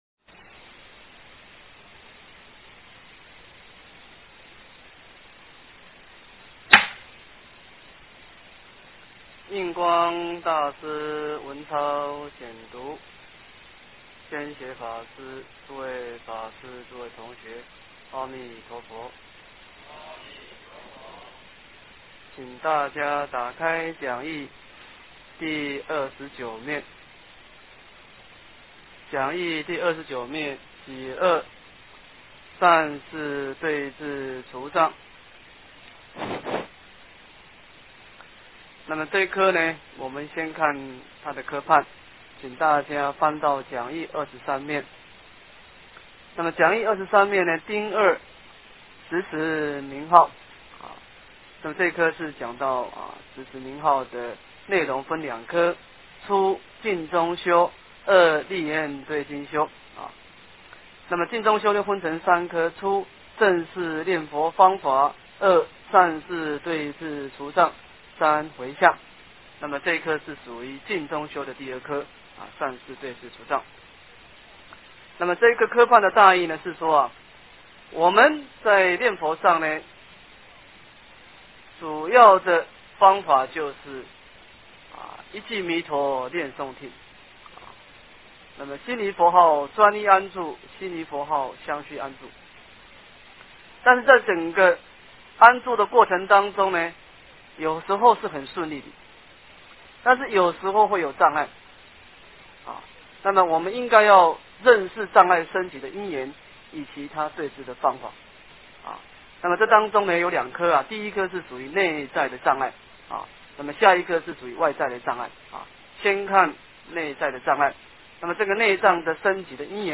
印光法师文钞13 诵经 印光法师文钞13--净界法师 点我： 标签: 佛音 诵经 佛教音乐 返回列表 上一篇： 印光法师文钞09 下一篇： 印光法师文钞14 相关文章 职场19顺境逆境--佛音大家唱 职场19顺境逆境--佛音大家唱...